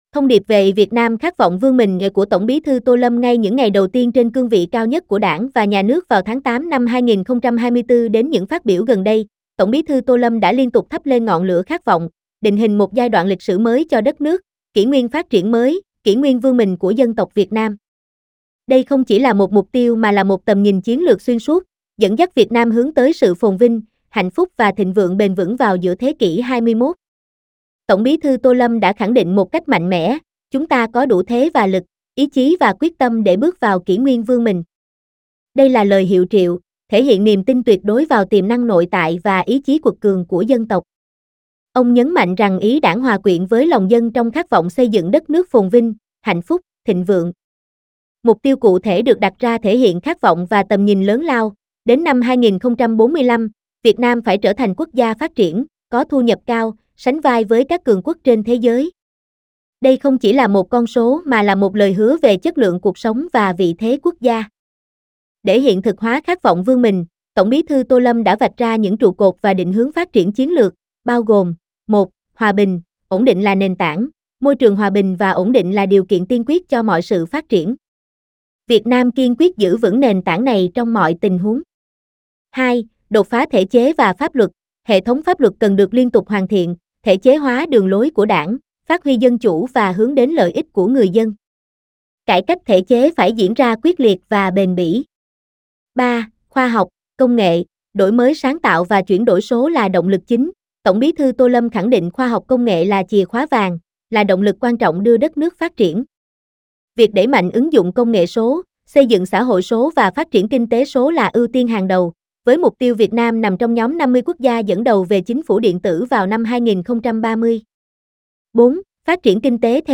SÁCH NÓI: "VIỆT NAM KHÁT VỌNG VƯƠN MÌNH"